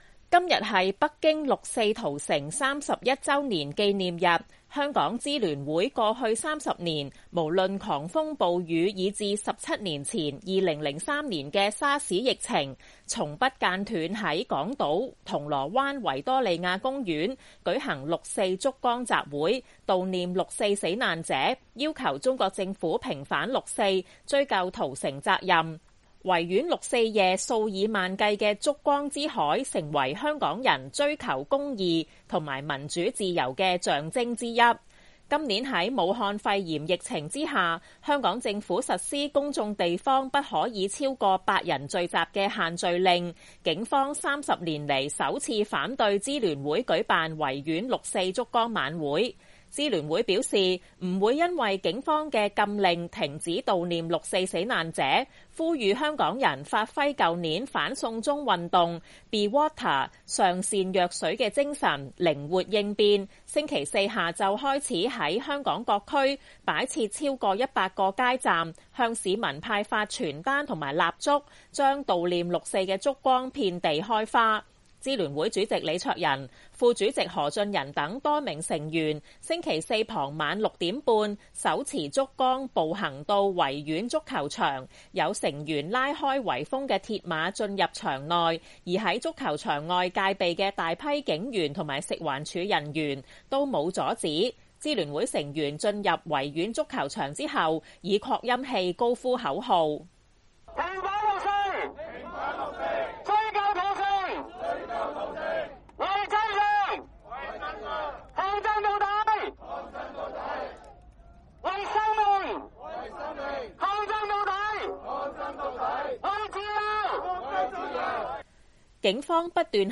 支聯會成員進入維園足球場後以擴音器高呼“平反六四、追究屠城、為真相抗爭到底、為生命抗爭到底、為自由抗爭到底”等口號。